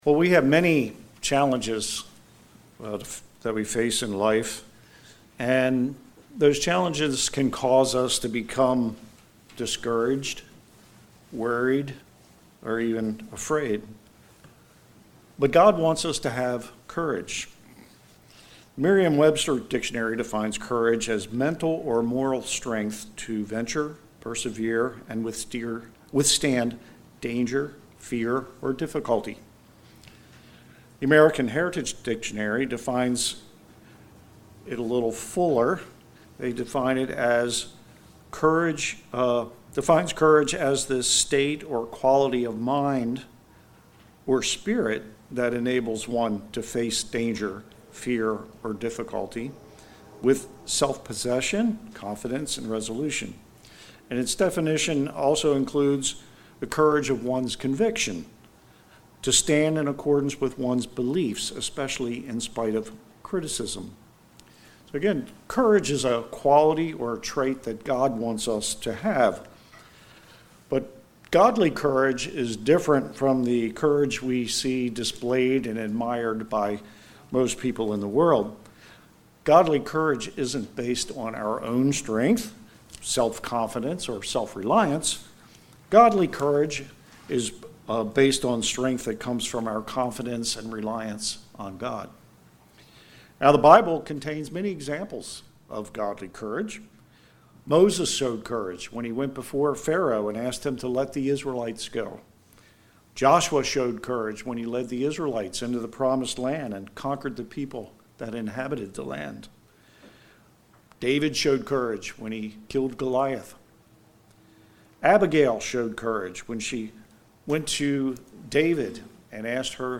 Sermons
Given in Delmarva, DE